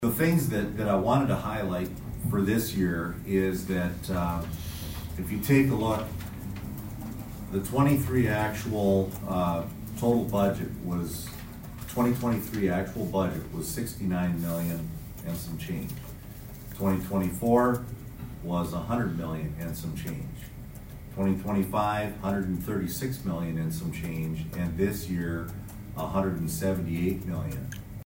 ABERDEEN, S.D.(HubCityRadio)- Toward the end of Monday’s Aberdeen City Council meeting, City Manager Robin Bobzien discuss the upcoming 2026 city budget.